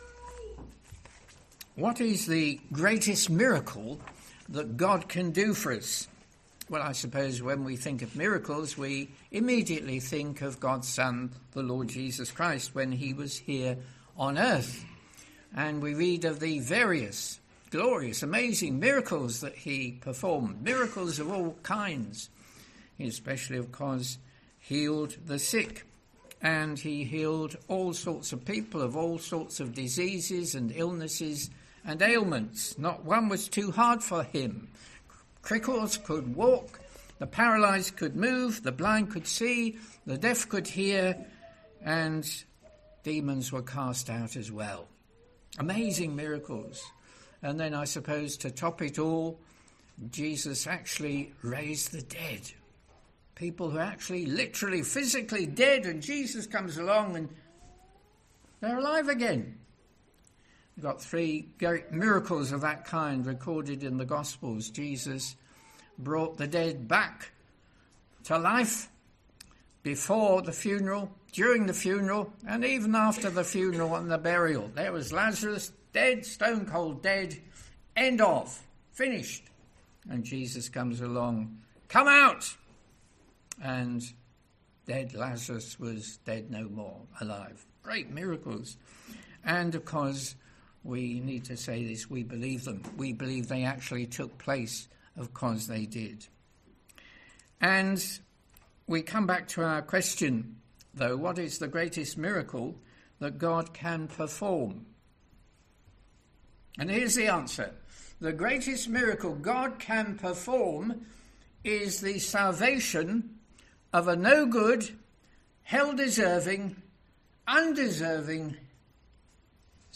Sermons: reverse order of upload